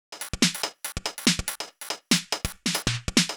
Index of /musicradar/uk-garage-samples/142bpm Lines n Loops/Beats
GA_BeatA142-11.wav